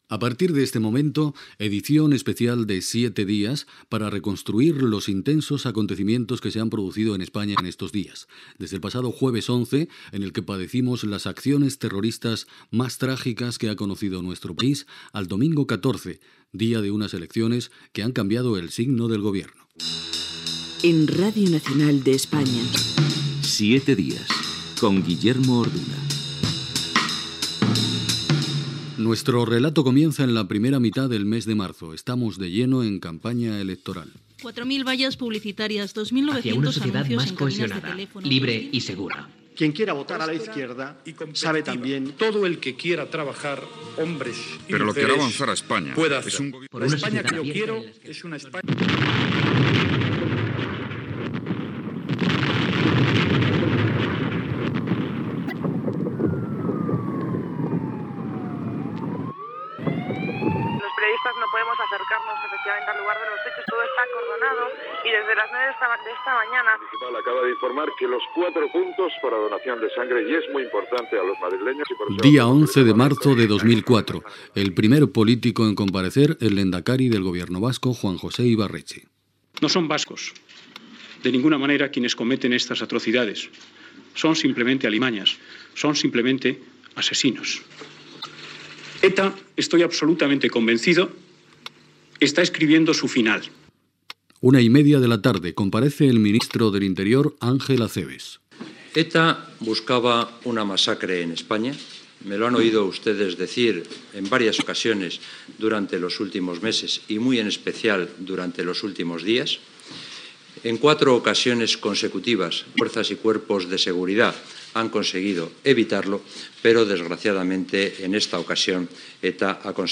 Presentació, identificació del programa, resum sonor de declaracions de diferents polítics fetes el dia 11 de març de 2004 després dels atemptats en tres trens de rodalies de RENFE a Madrid.
Informatiu